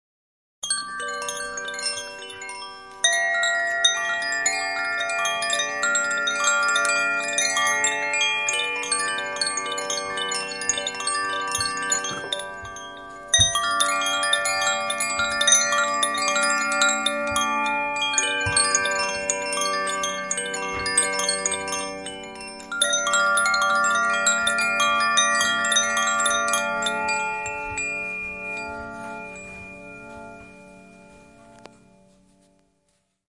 描述：声音来自两个koshi风铃水和火，序列：fireaquafireaquafiraaqua，用联想Moto Z Play录制。
Tag: 放松 水产 风编钟